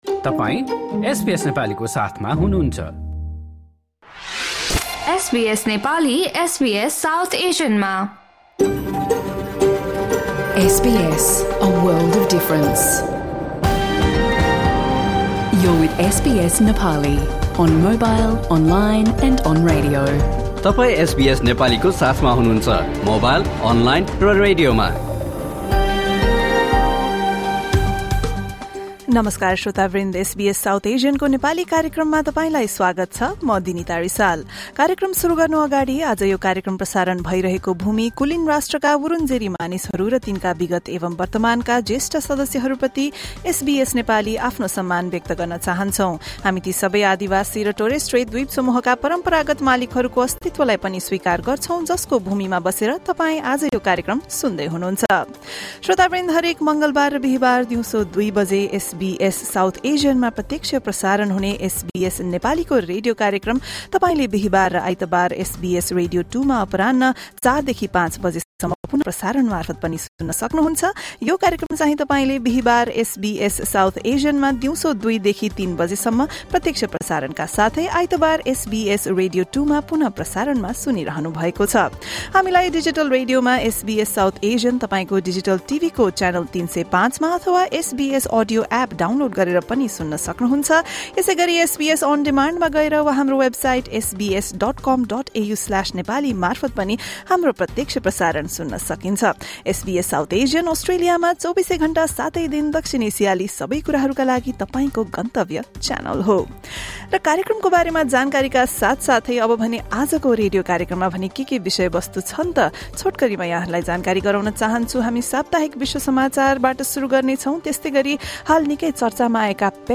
Listen to the SBS Nepali radio program, first aired on SBS South Asian on Thursday, 26 March 2026.